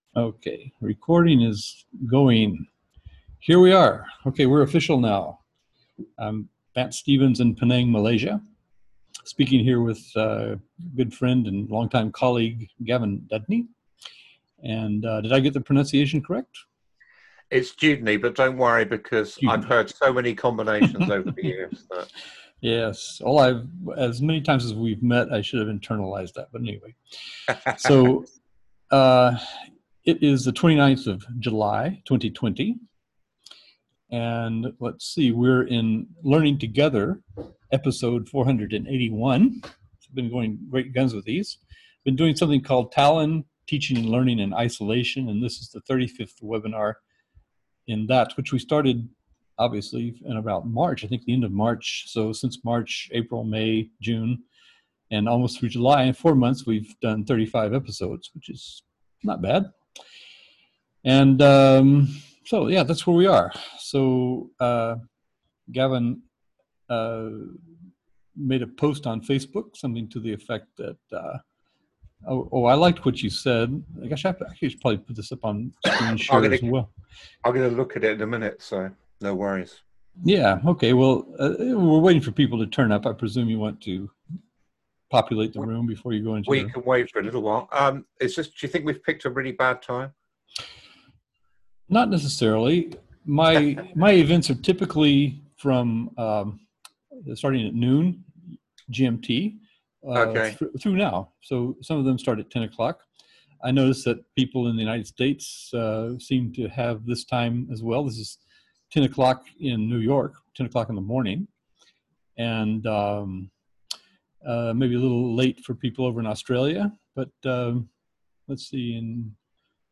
Learning2gether Episode 478 and TALIN webinar #32